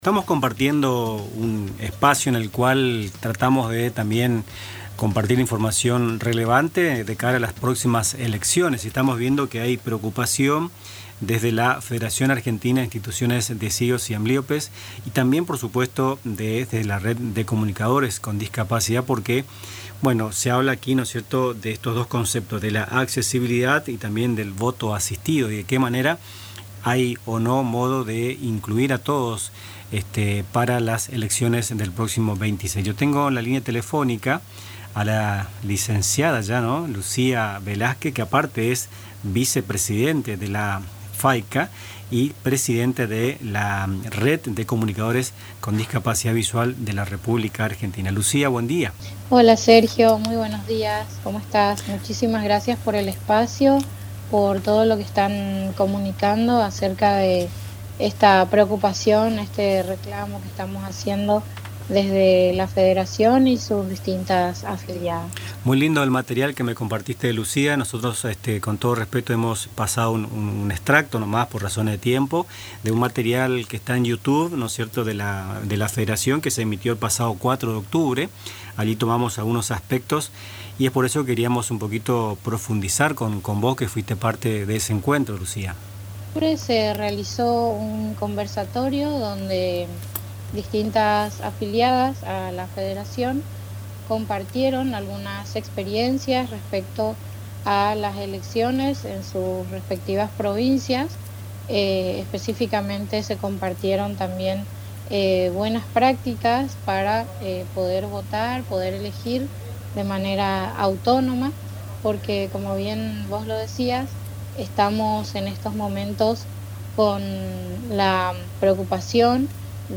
En diálogo con Nuestras Mañanas